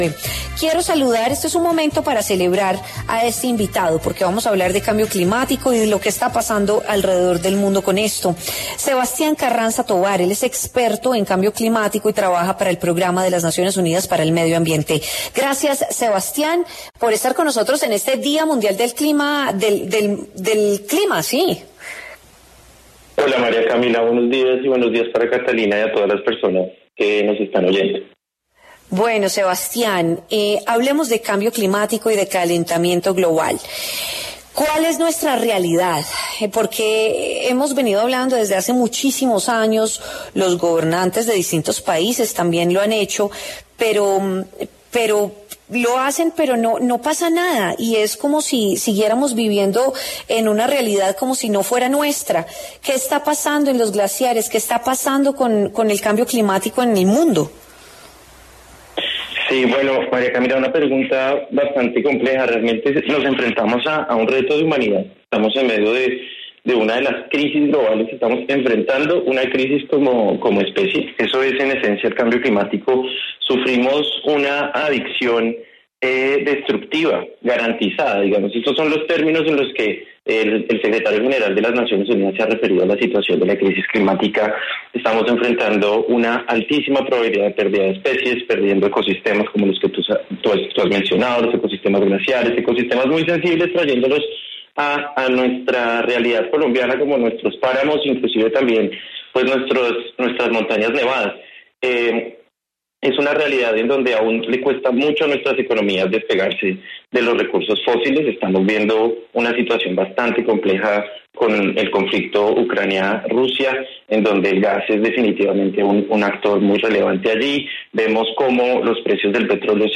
experto en cambio climático, explicó en W Fin de Semana los retos que enfrenta la humanidad como especie para combatir el cambio climático.